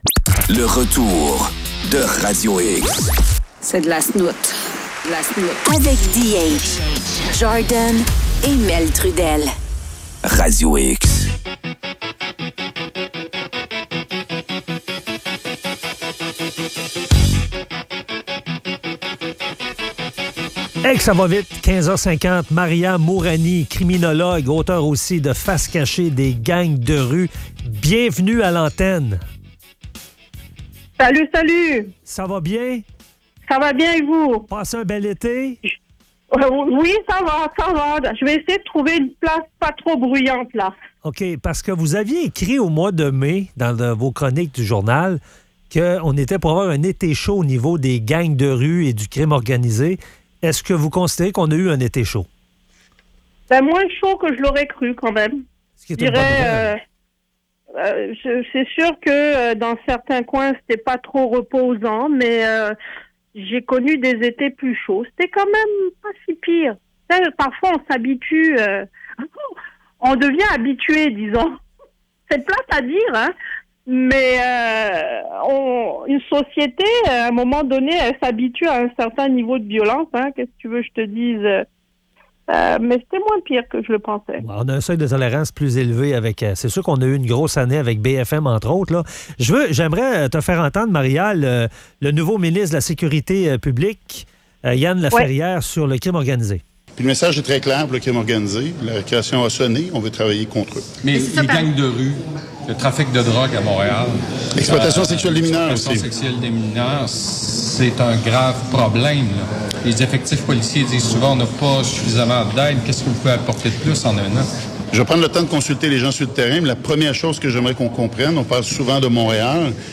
Entrevue avec la criminologue Maria Mourani.